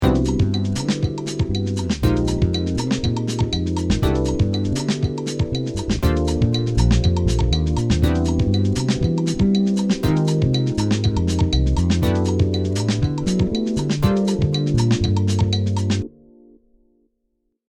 Fclef 5 string Vintage ‘60
Samba.mp3